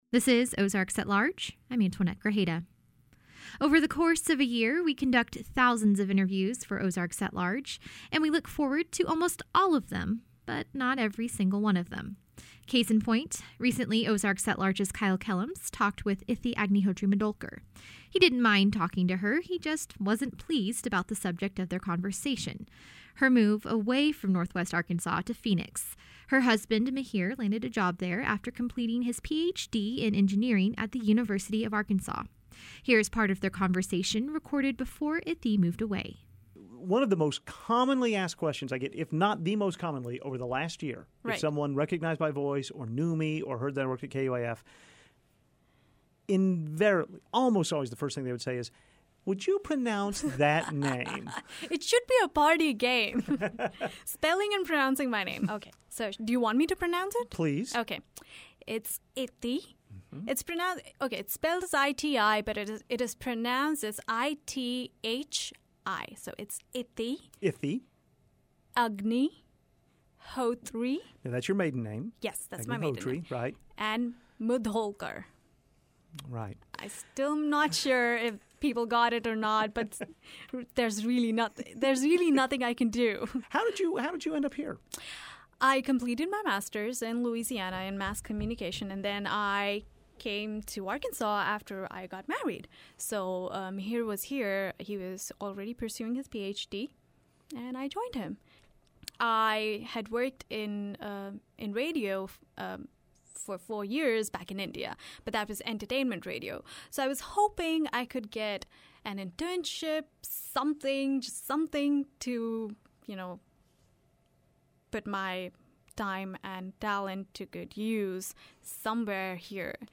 exit interview of sorts